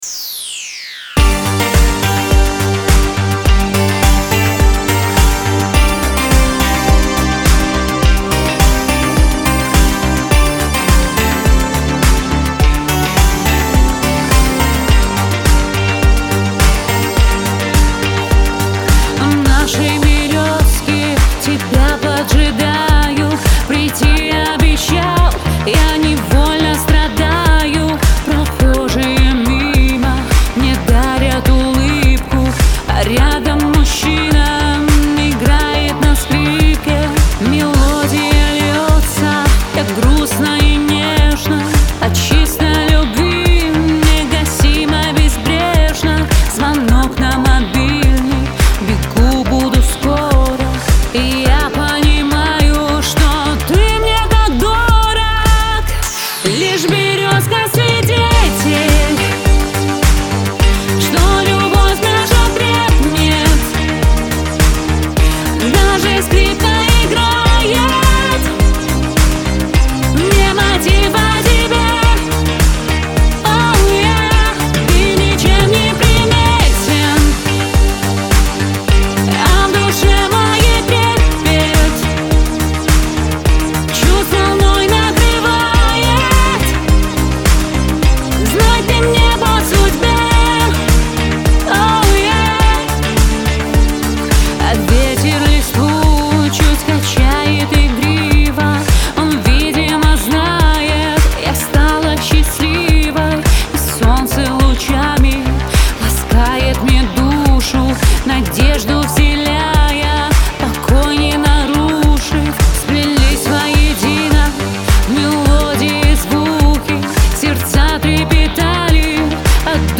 Лирика , эстрада , pop